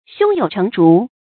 注音：ㄒㄩㄥ ㄧㄡˇ ㄔㄥˊ ㄓㄨˊ
胸有成竹的讀法